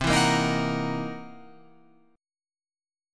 C7#9b5 Chord
Listen to C7#9b5 strummed